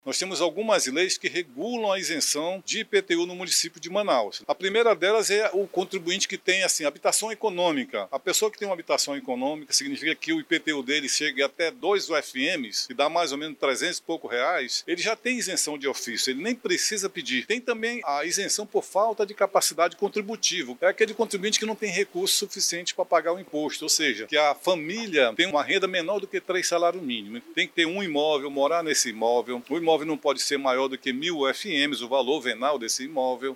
O subsecretário da Receita, Armínio Pontes, destaca alguns critérios que dão direito à isenção de IPTU, na cidade de Manaus.